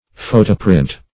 Photoprint \Pho"to*print`\, n. Any print made by a photomechanical process.